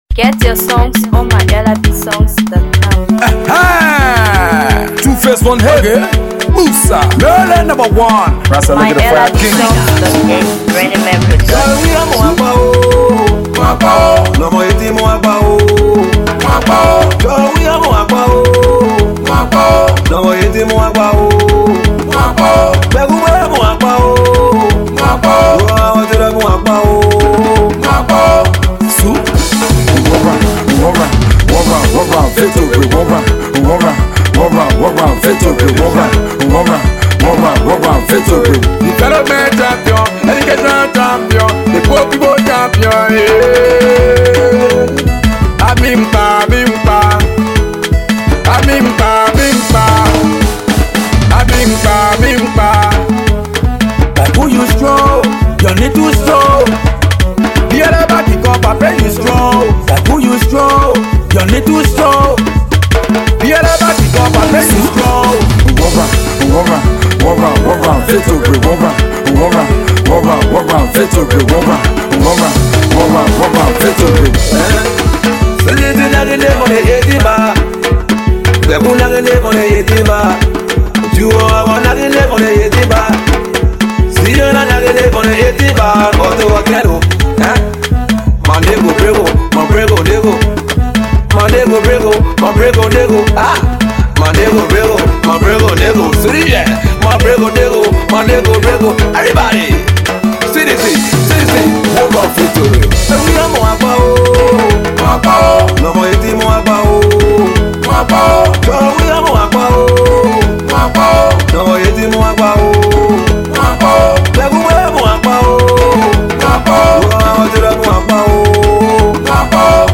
Afro Pop
Political Banger